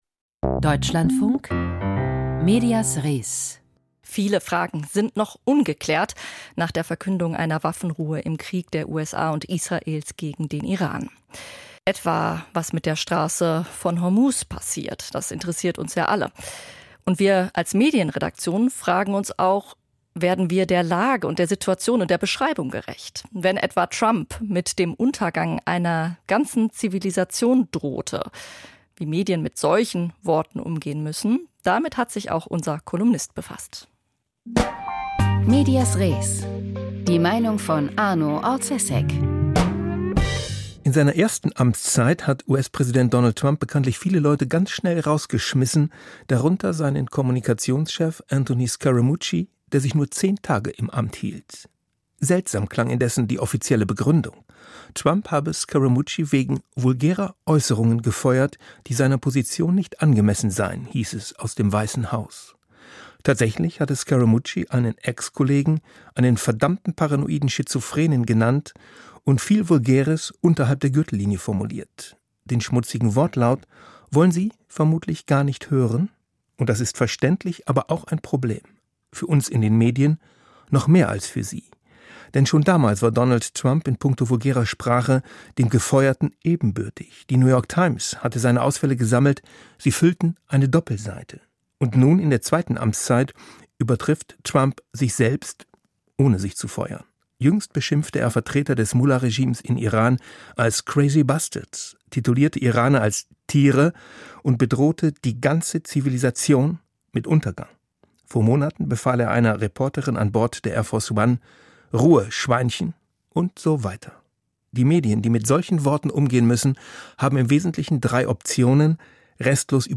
Kolumne: Trumps verwilderte Sprache